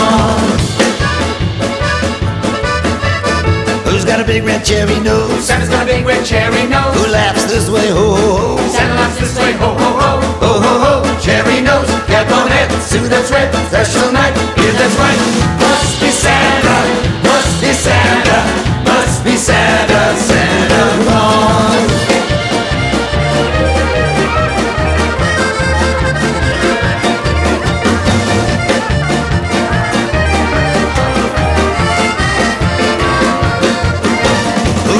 # Holiday